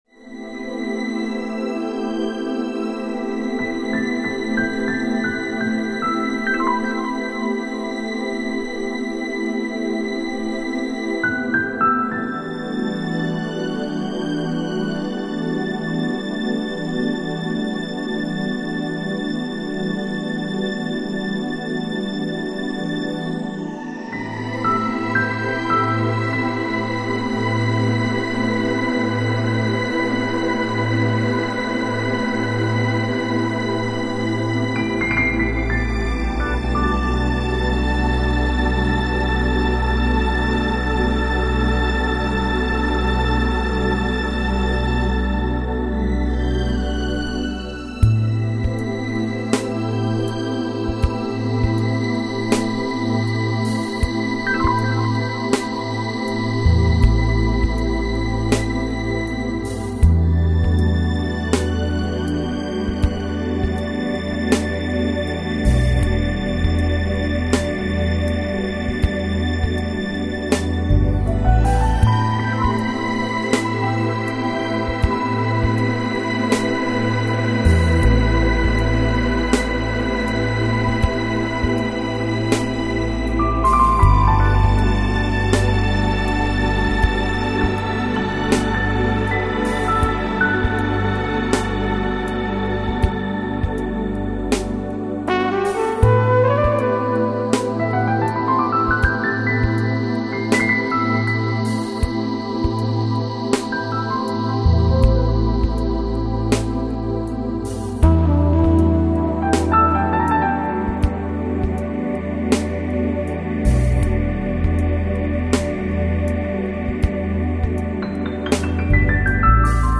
Жанр: Electronic, Chill Out, Lounge